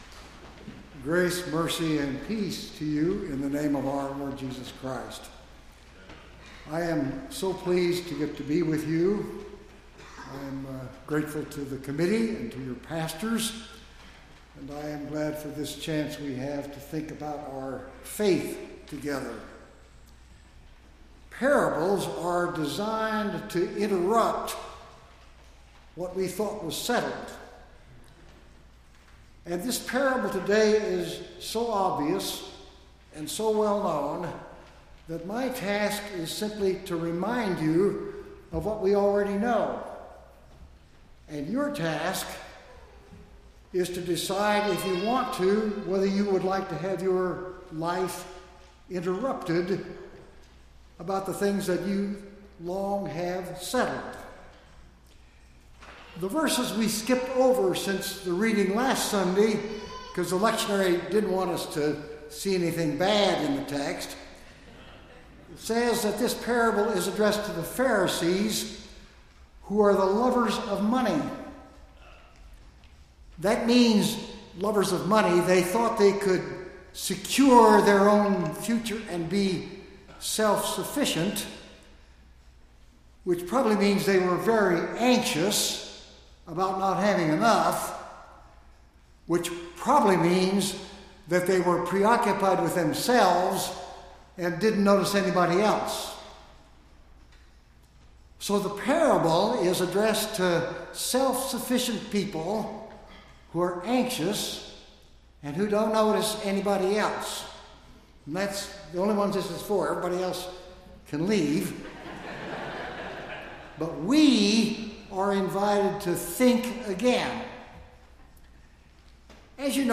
9-25-16-sermon.mp3